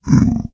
sounds / mob / zombiepig / zpig3.ogg